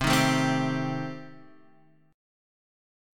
Cm6 Chord